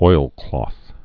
(oilklôth, -klŏth)